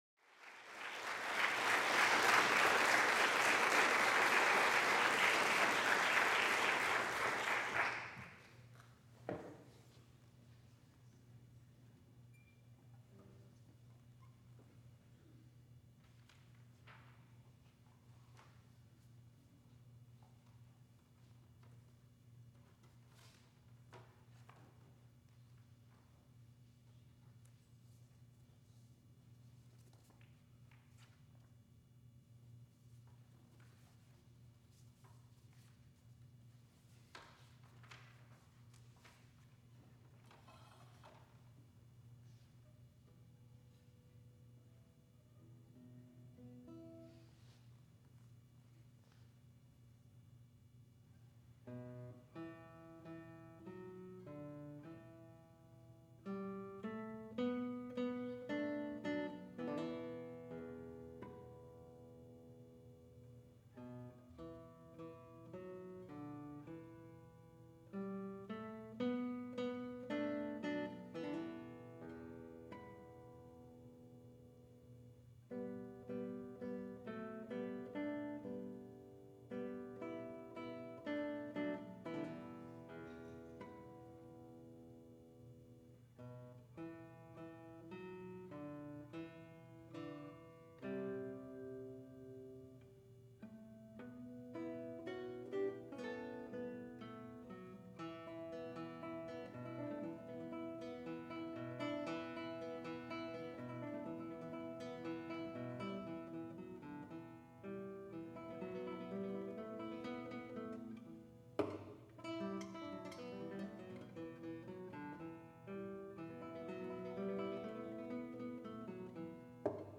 for Guitar (2006)
A quick section follows, in guitar-picking style.